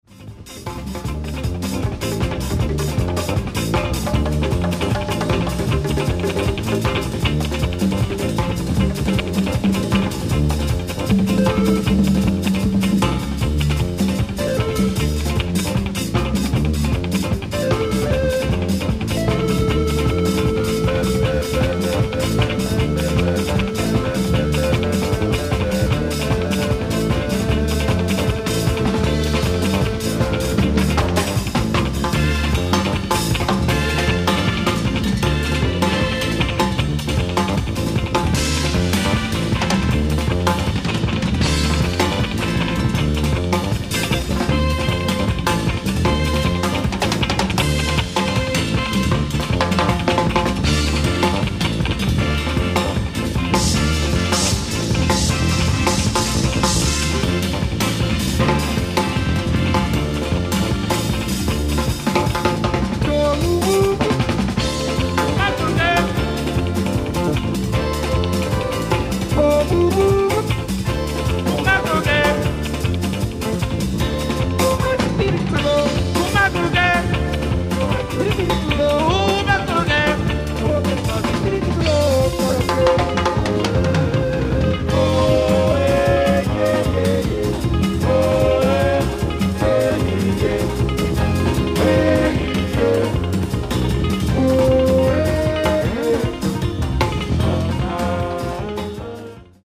Live At Queens Hall, Endiburg, Scotland
SOUNDBOARD RECORDING